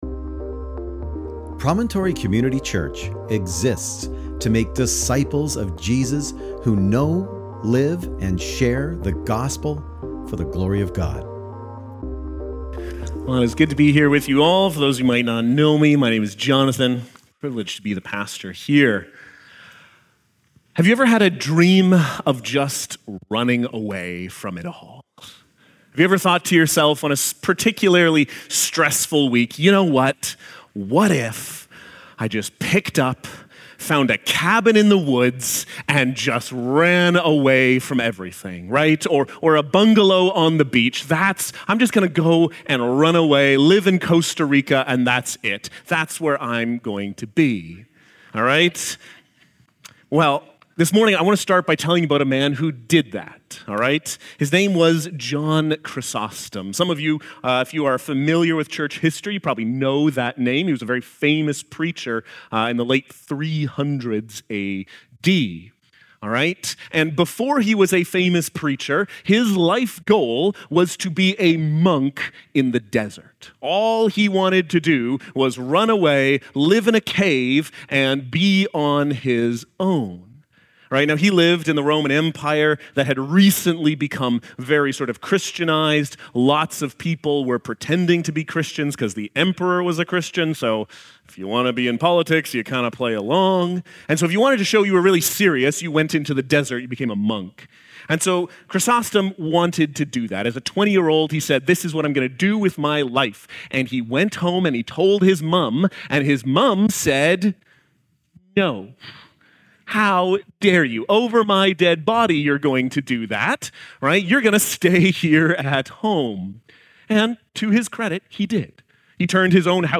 Sermon Transcript: (transcribed with AI) Well, it's good to be here with you all.